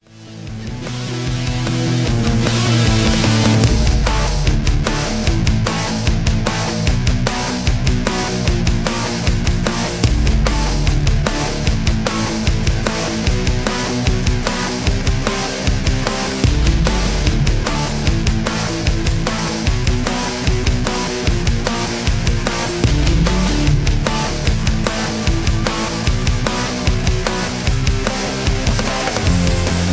Tags: a, backing tracks , karaoke , sound tracks